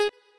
left-synth_melody06.ogg